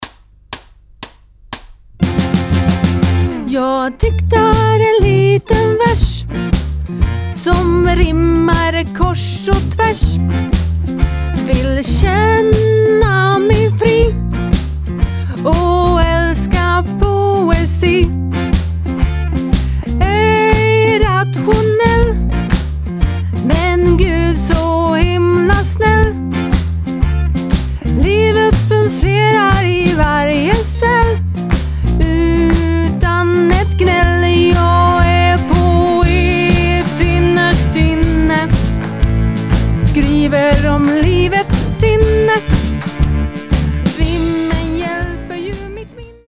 Sångversion